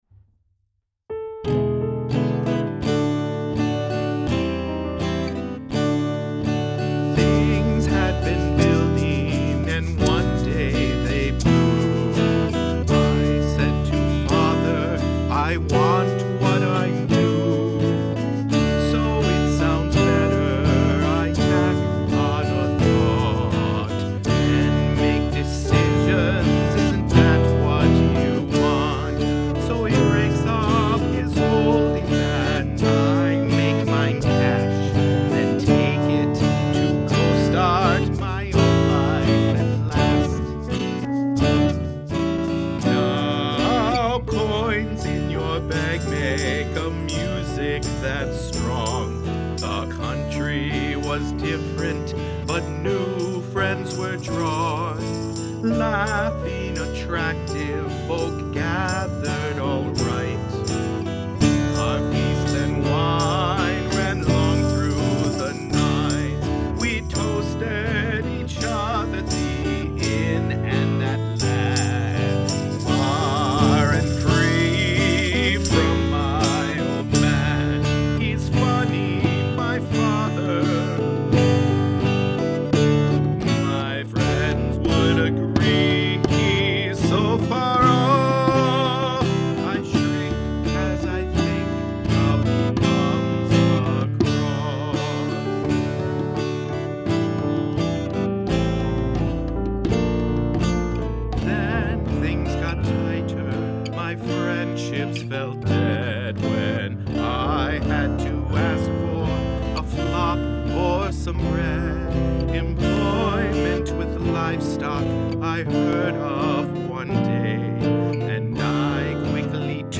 This is set for a solo baritone-like voice, plus guitar, piano, bass, and percussion.